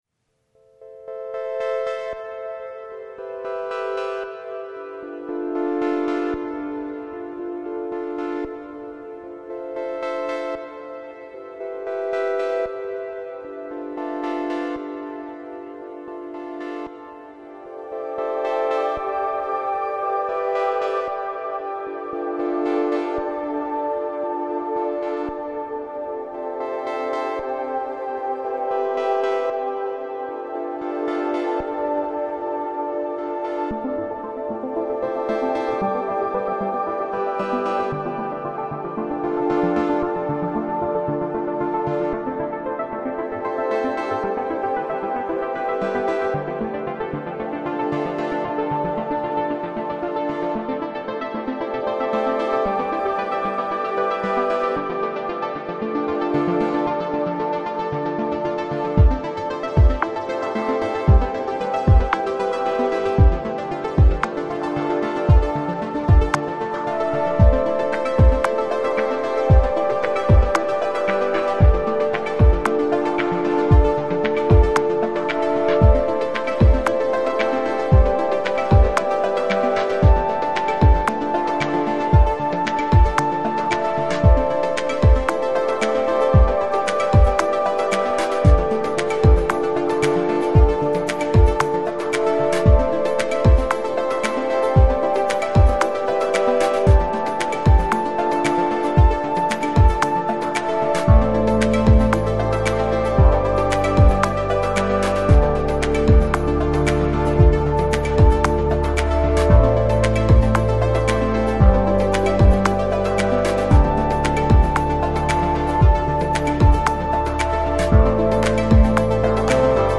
Lounge, Chill Out, Downtempo, Ambient